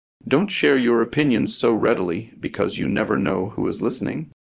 Text-to-Speech
Add clones